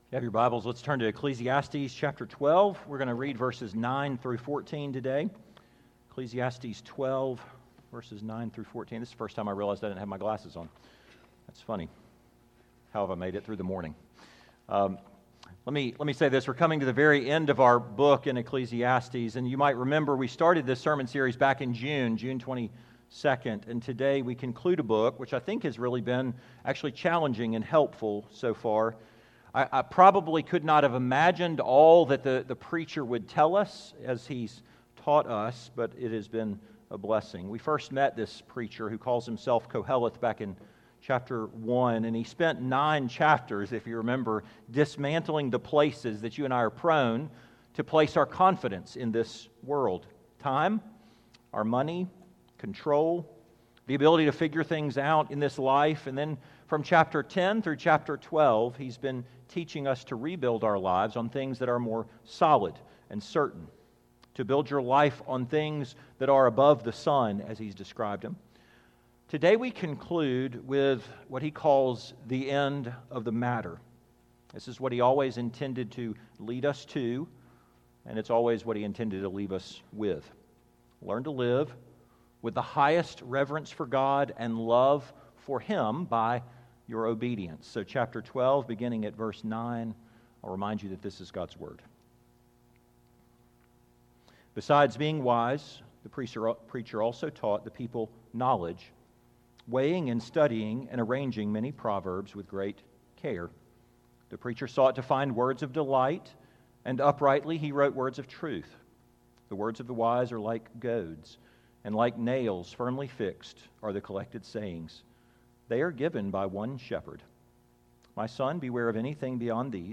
2025 The End of the Matter Preacher